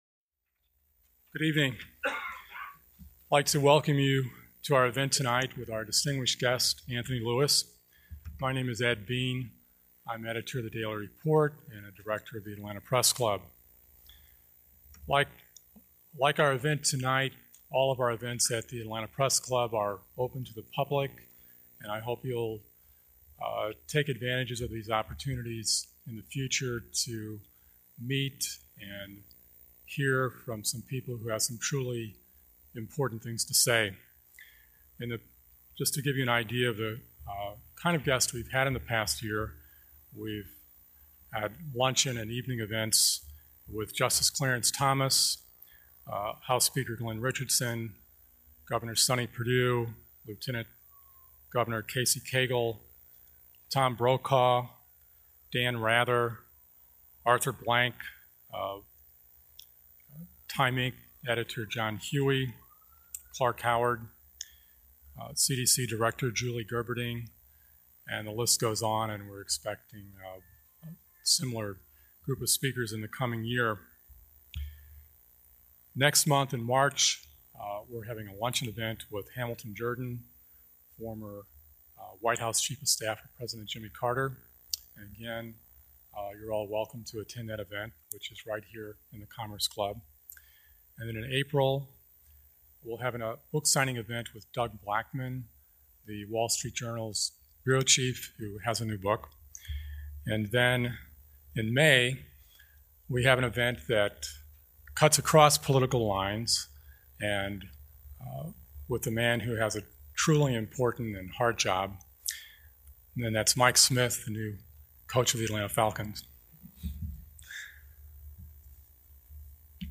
Show notes Pulitzer Prize Winner Anthony Lewis spoke at The Atlanta Press Club on Feb. 13, 2008. Two-time Pulitzer Prize winner Anthony Lewis was a columnist for The New York Times op-ed page from 1969 through 2001.